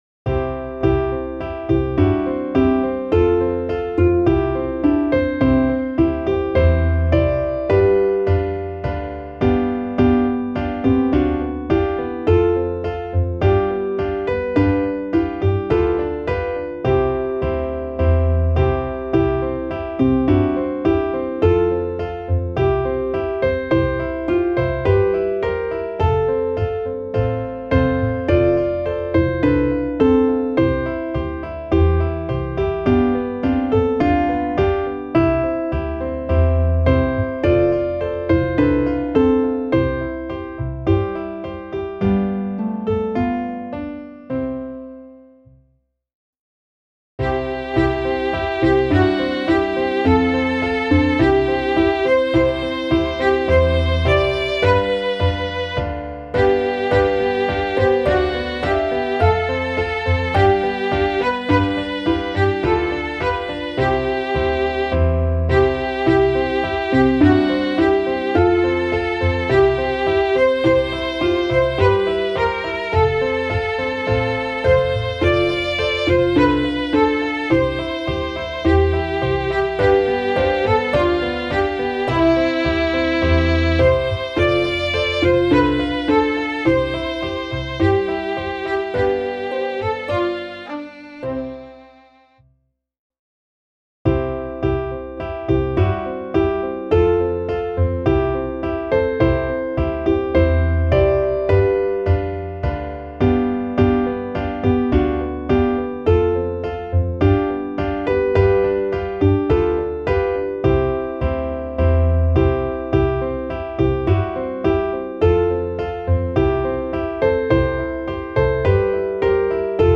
Klaviersätze T bis V